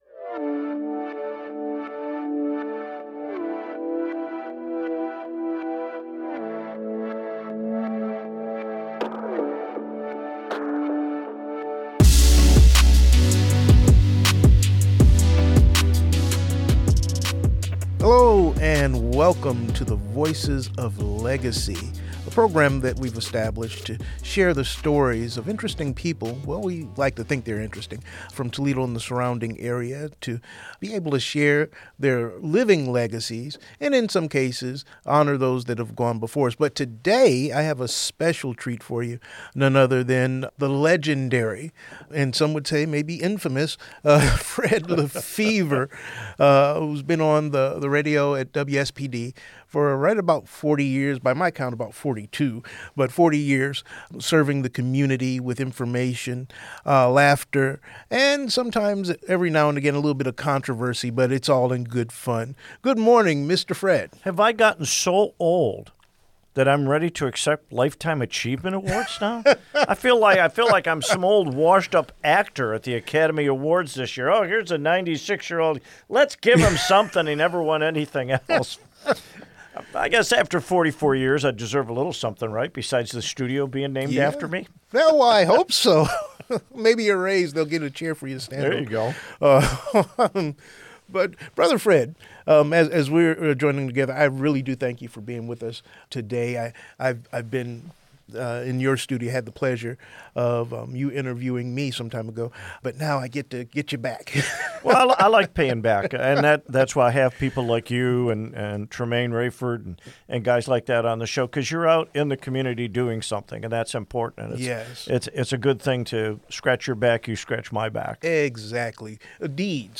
40 Years of Radio Excellence: A Conversation